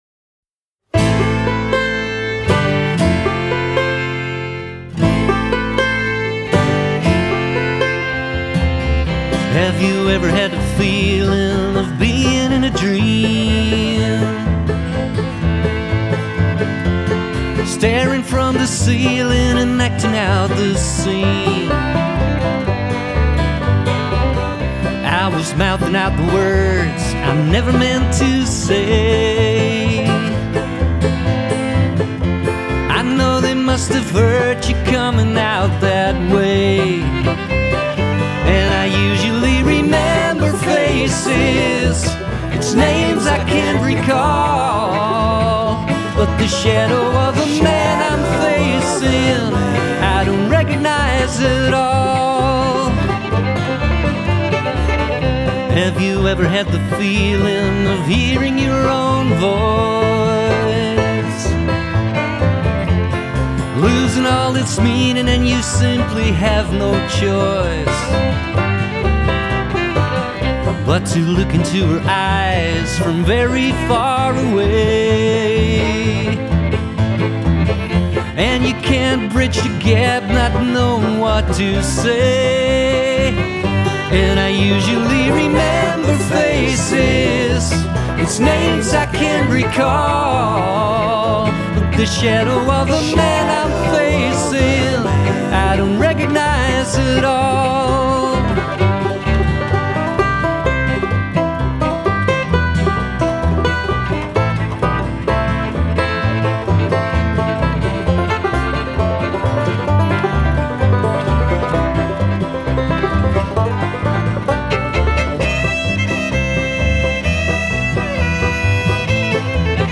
**GENRE: SMOOTH BLUEGRASS